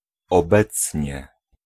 Ääntäminen
US : IPA : [naʊ]